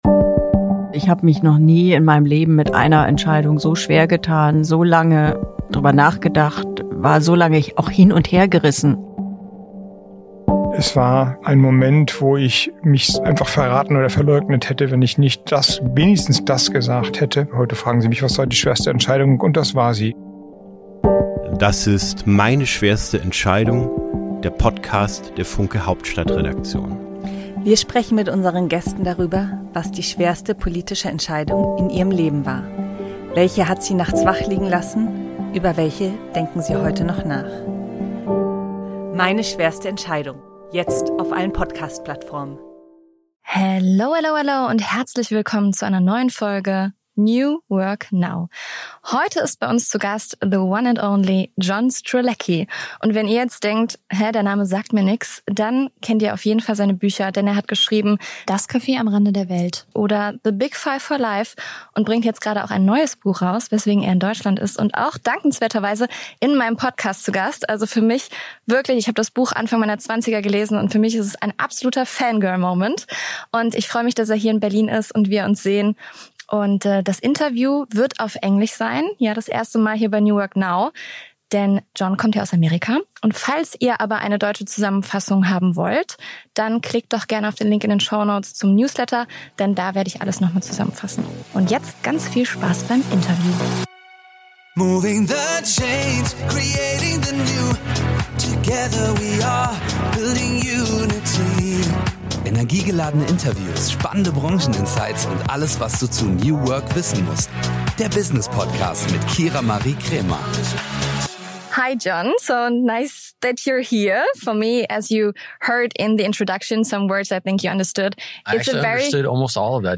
Eine Premiere bei New Work Now: Im Podcast zu Gast ist der amerikanische Bestsellerautor und Speaker John Strelecky - daher ist diese Folge auf Englisch.